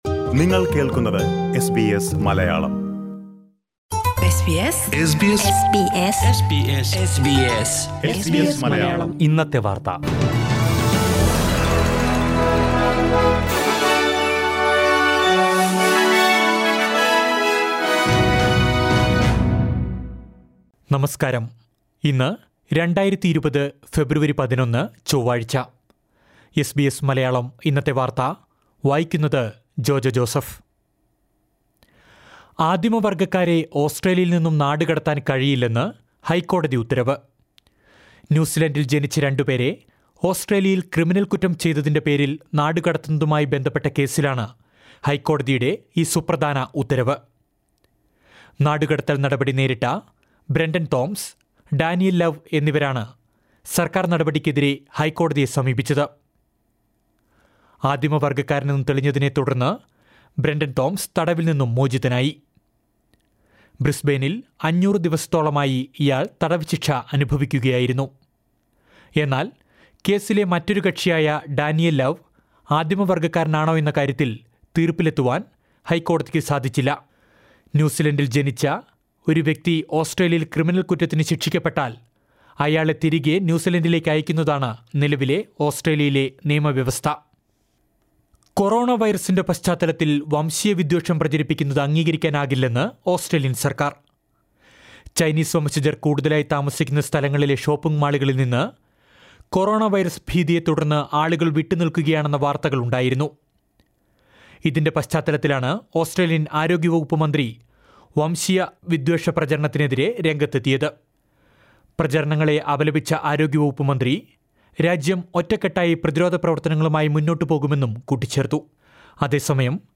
2020 ഫെബ്രുവരി 11ലെ ഓസ്ട്രേലിയയിലെ ഏറ്റവും പ്രധാന വാർത്തകൾ കേൾക്കാം…